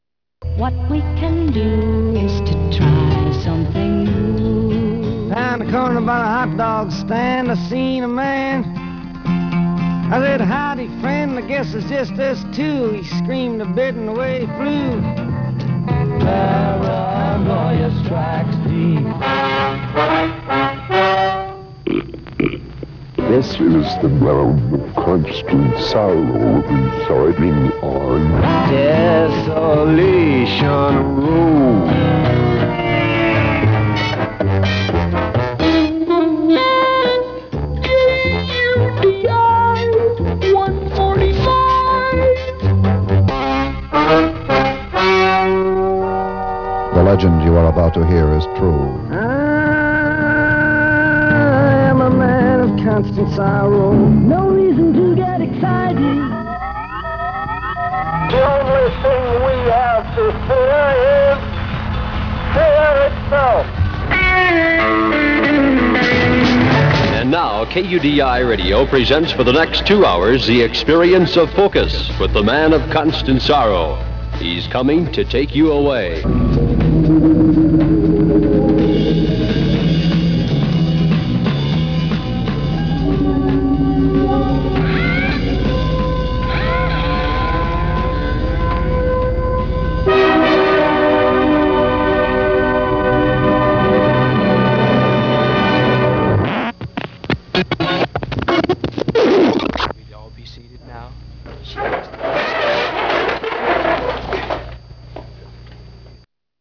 collage style radio show